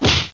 Amiga 8-bit Sampled Voice
punch.mp3